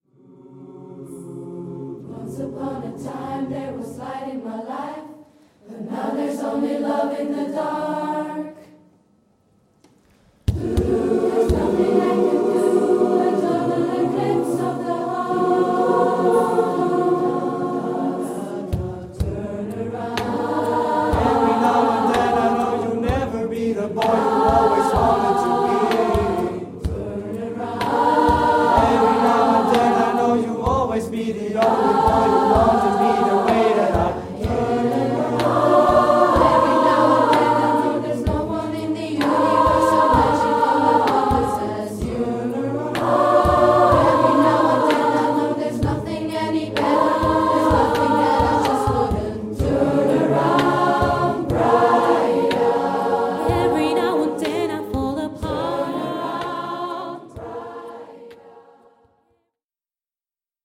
a Cappella
SMATB & Solo W